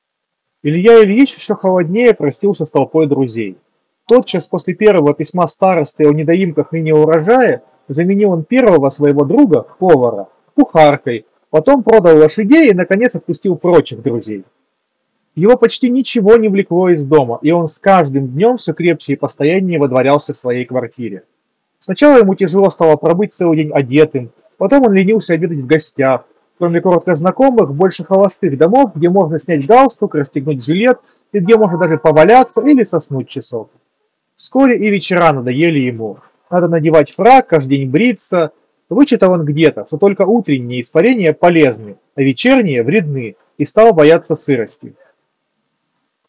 3-Micnoise.mp3